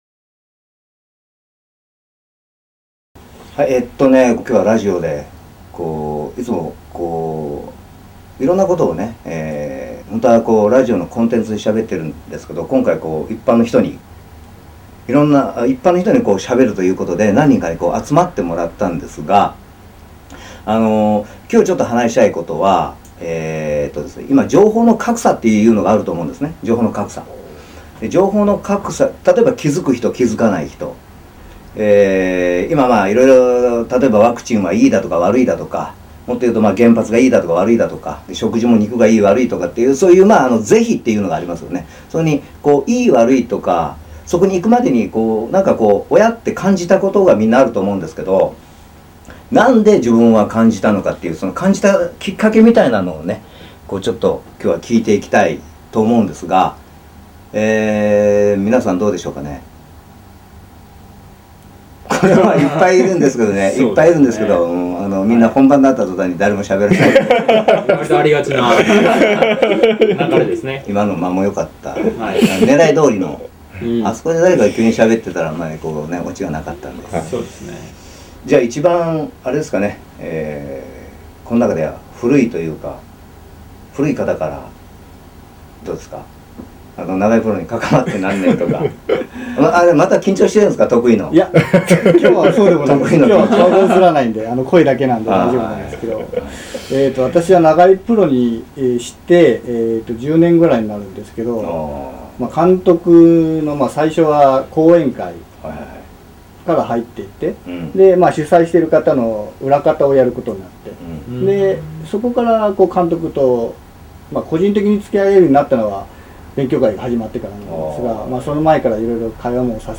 ５人が語る。